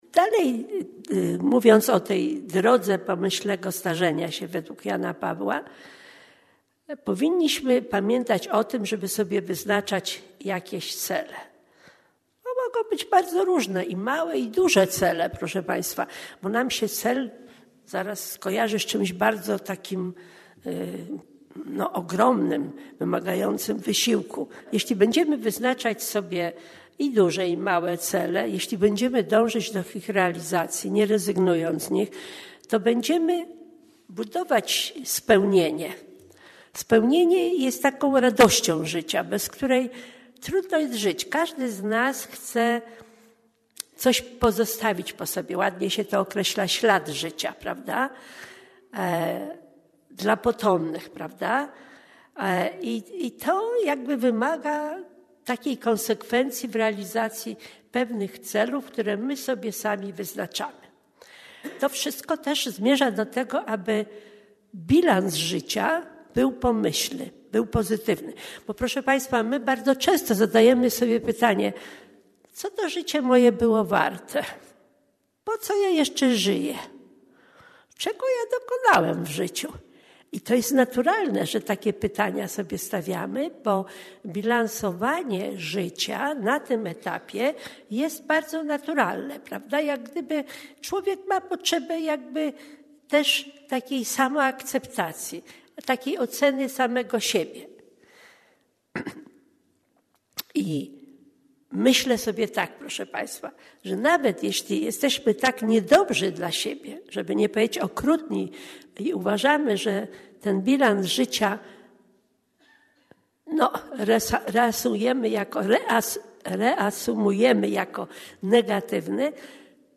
Zapraszamy do wysłuchania wykładów w ramach IV zjazdu Uniwersytetu Trzeciego Wieku PWT.